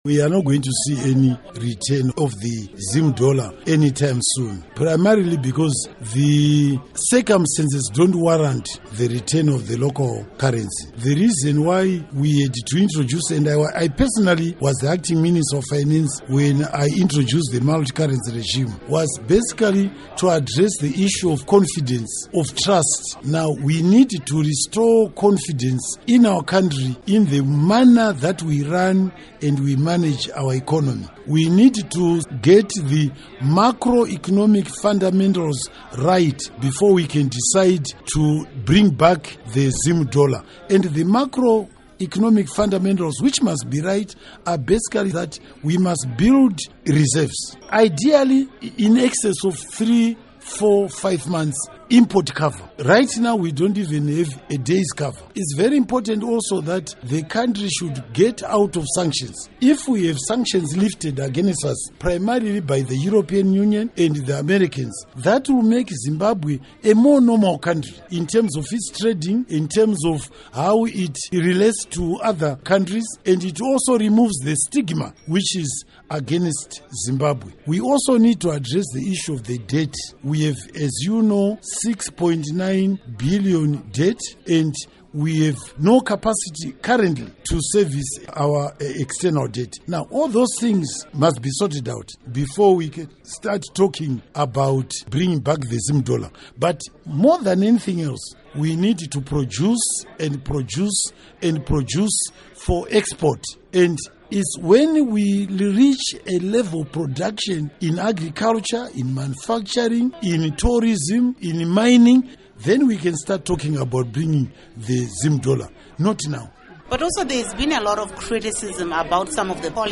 Interview With Hon Patrick Chinamasa